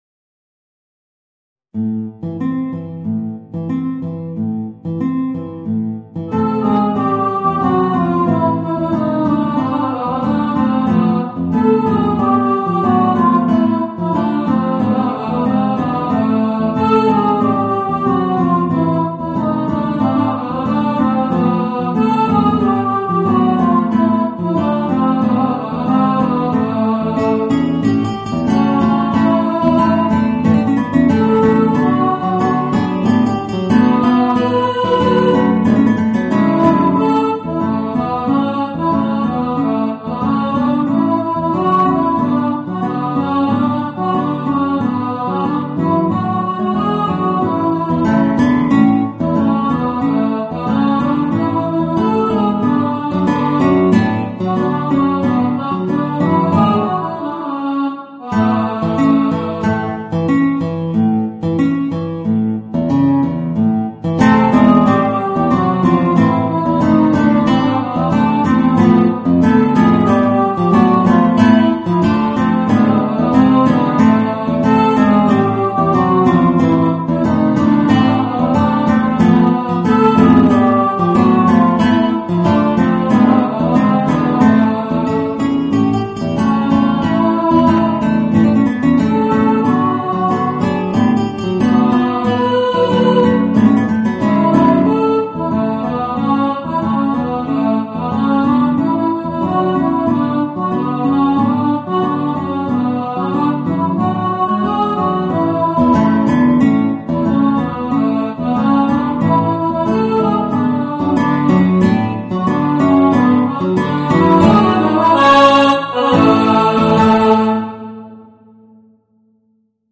Voicing: Guitar and Alto